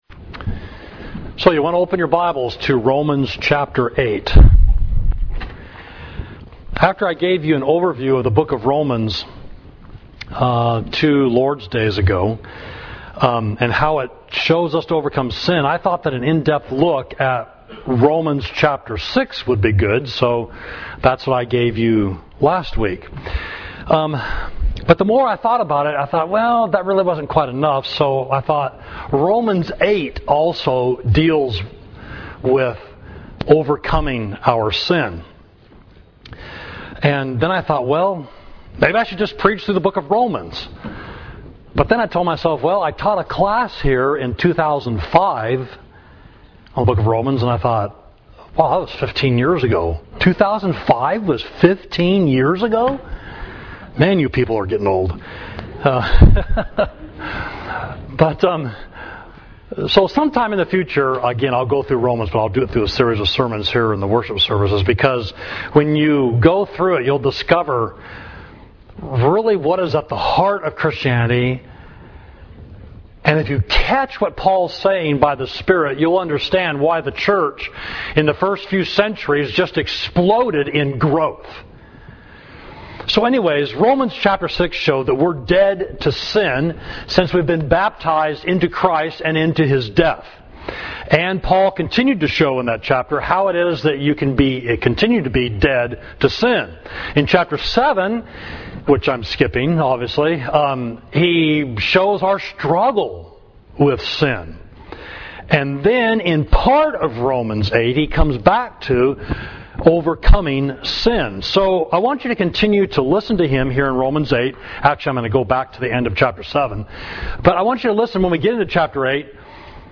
Sermon: Set Your Mind on the Things of the Spirit, Romans 8 – Savage Street Church of Christ